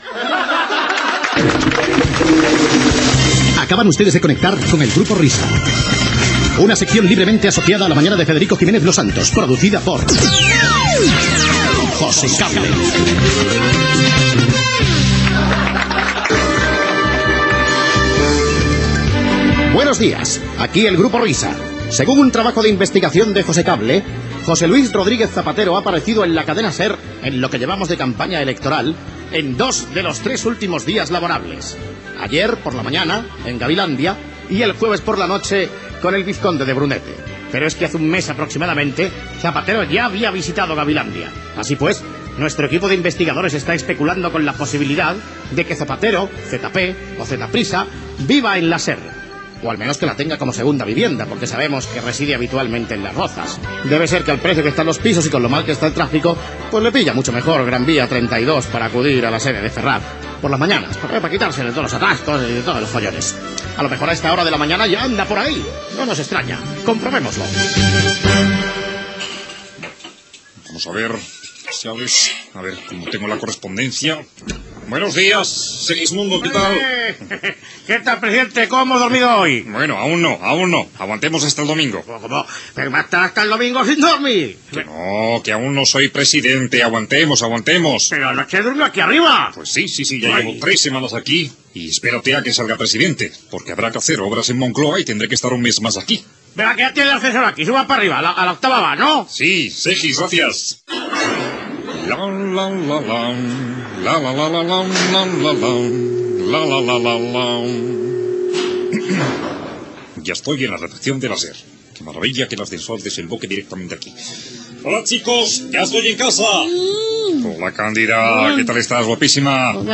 Info-entreteniment
Programa presentat per Federico Jiménez Losantos.